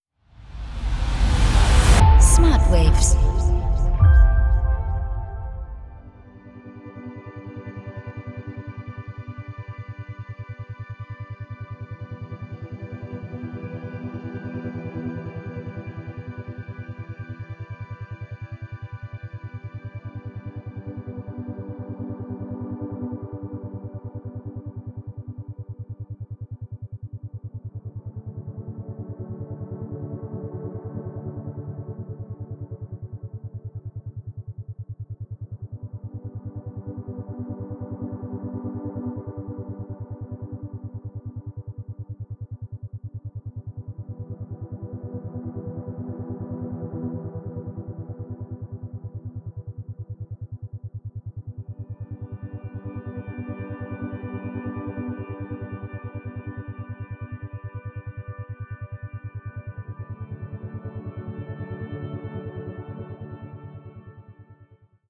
Isochrone Beats